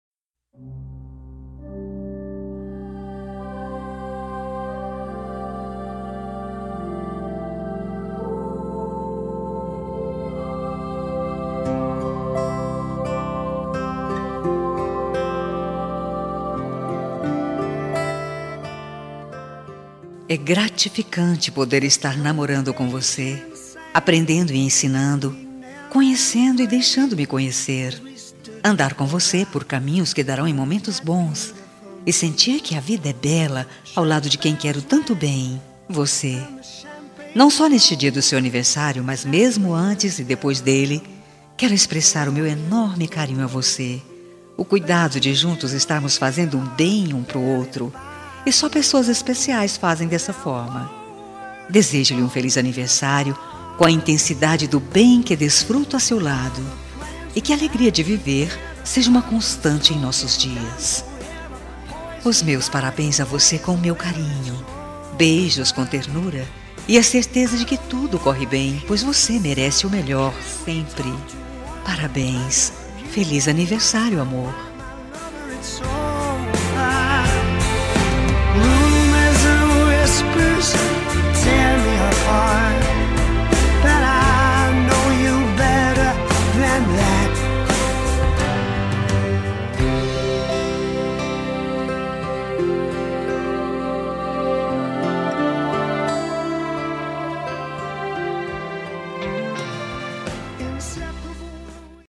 Telemensagem de Aniversário Romântico – Voz Feminina – Cód: 202118 – Suave